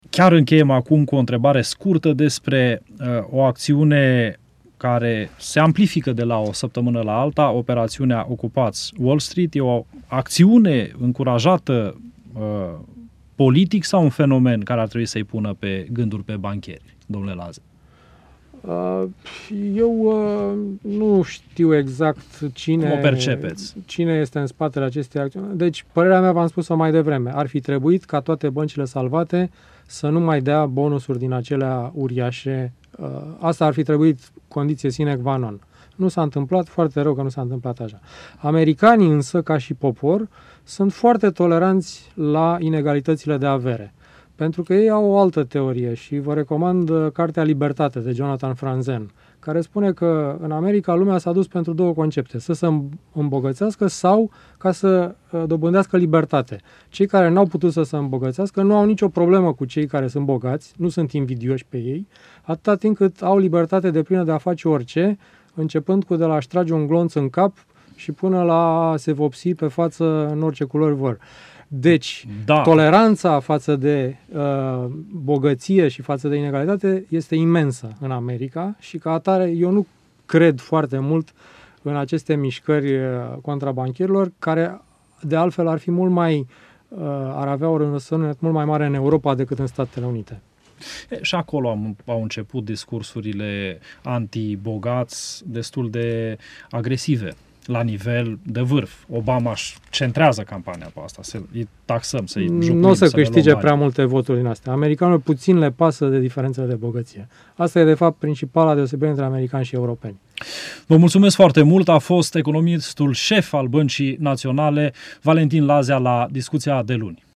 Ce spune la Discutia de Luni economistul sef al BNR, Valentin Lazea, despre operatiunea „Ocupati Wall-Street”: